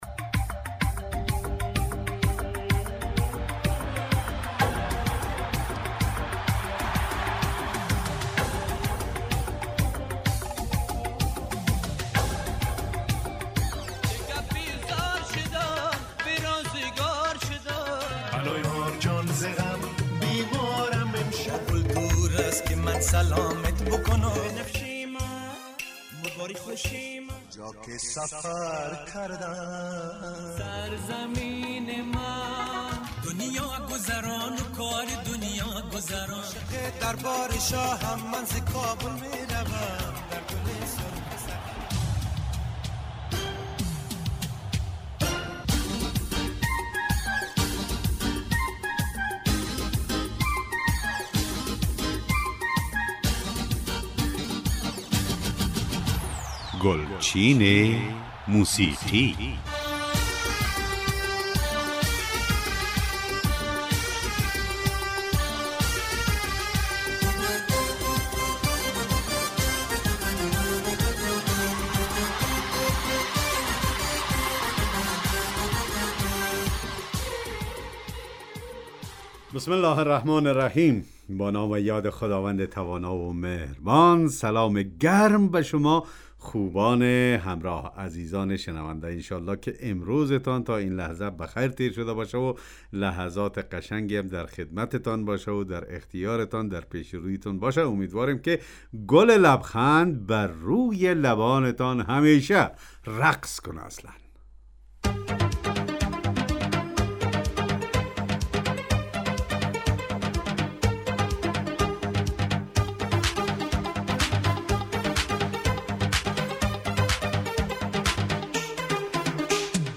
ترانه های درخواستی